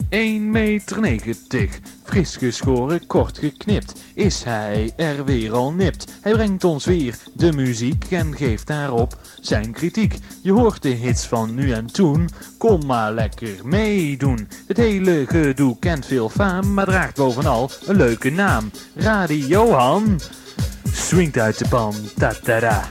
Hier enkele jingles.